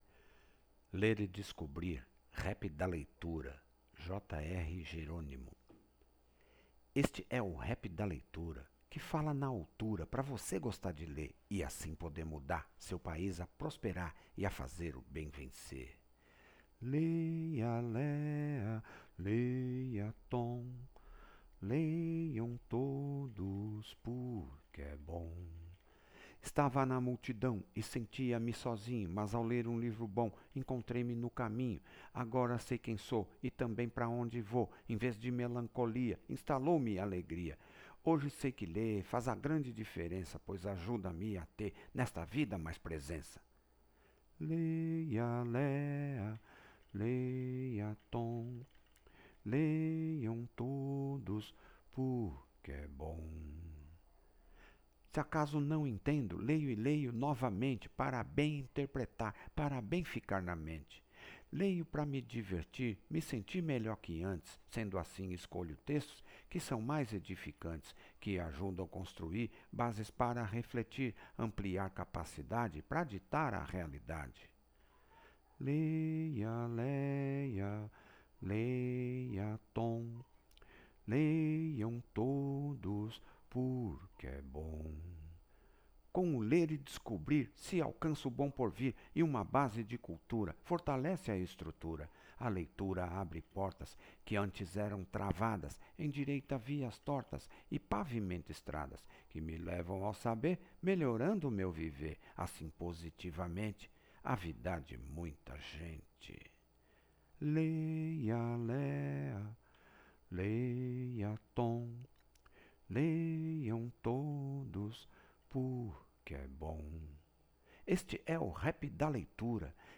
rap_da_leitura.mp3